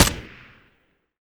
shoot_sil.ogg